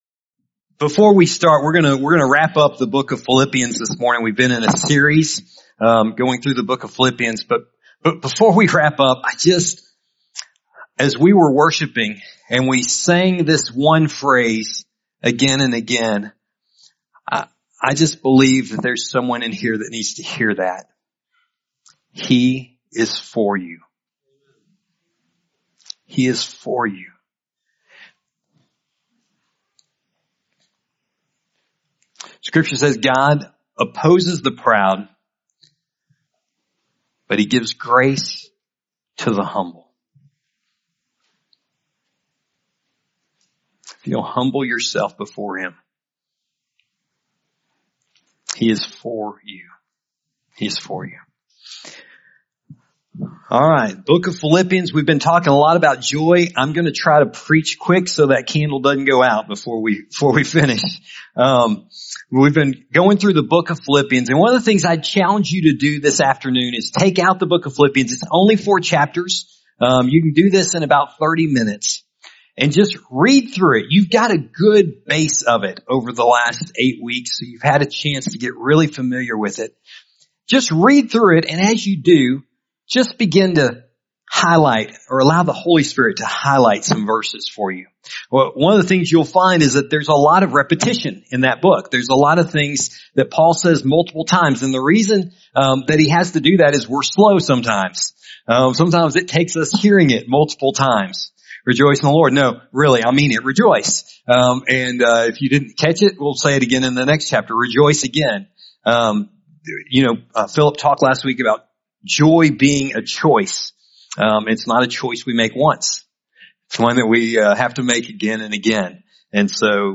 This is a message that calls us to grow in generosity and experience the freedom and purpose it brings.